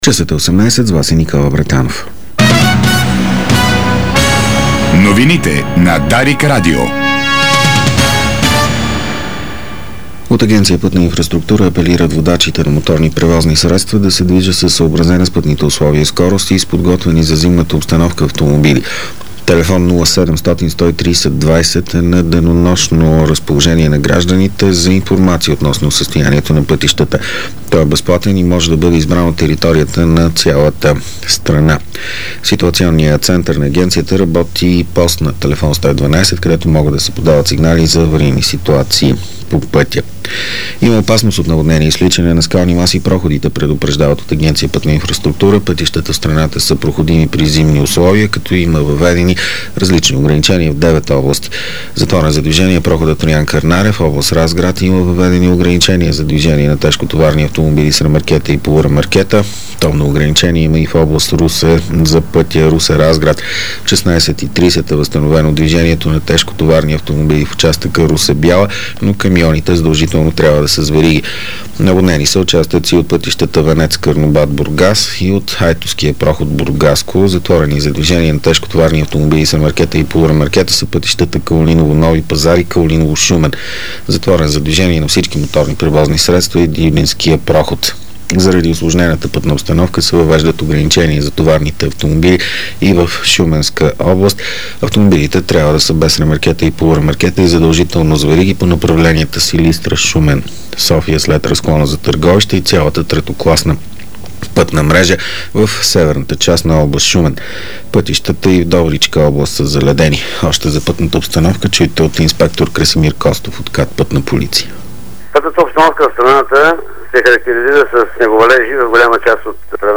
Обзорна информационна емисия - 20.12.2009